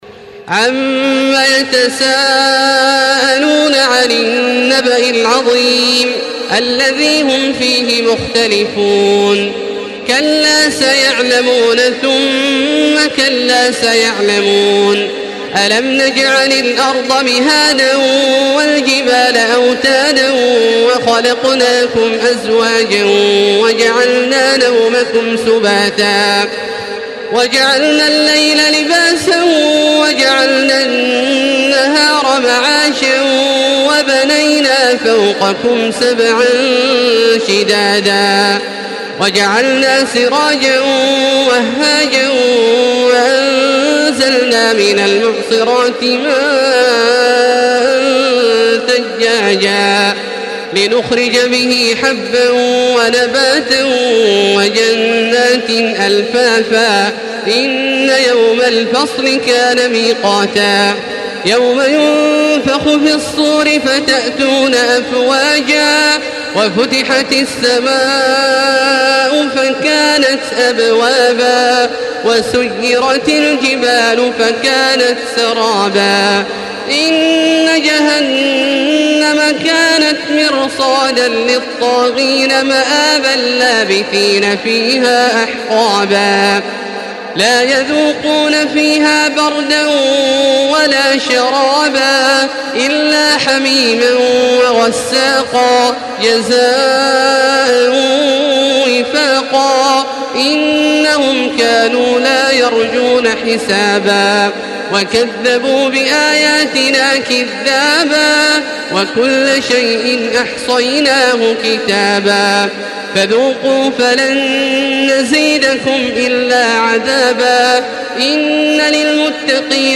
Surah Nebe MP3 in the Voice of Makkah Taraweeh 1435 in Hafs Narration
Murattal